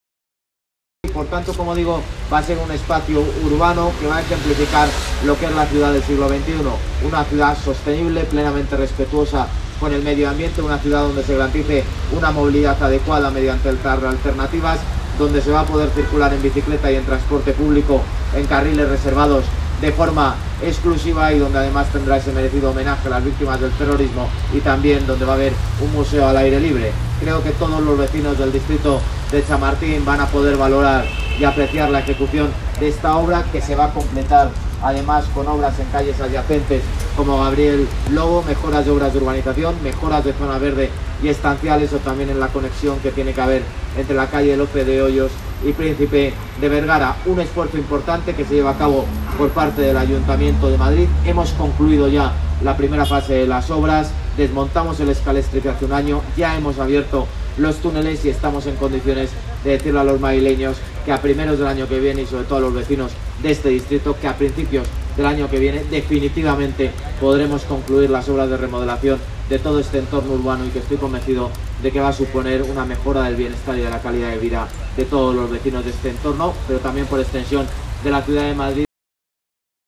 Nueva ventana:Almeida explica los trabajos de reurbanización del eje Joaquín Costa-Francisco Silvela